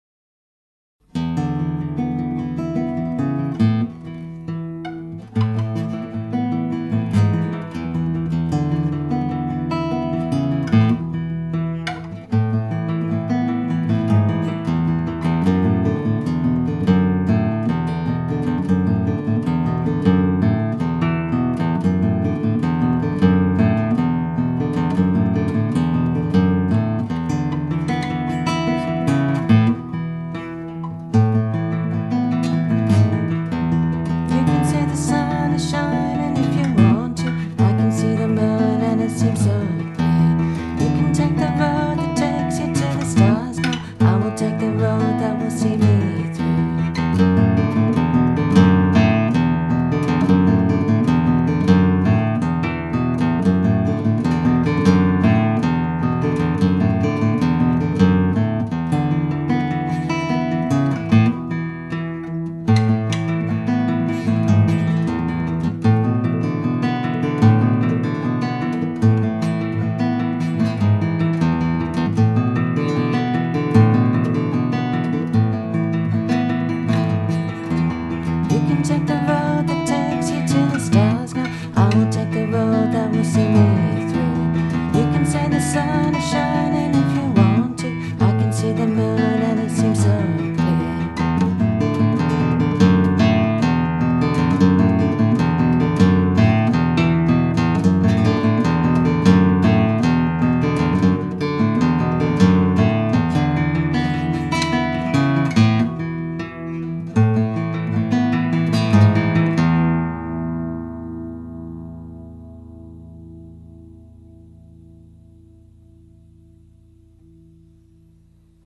The tracks below have zero separation between voice and guitar mics – just grabbed on a basic recorder, with no compression or EQ etc. Since then, I’ve learned a lot more about music  production and my preferred set up now is to record on a Zoom LS8, then re-amp through pedals (avoiding a DAW as much as possible).